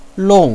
lòng
long4.wav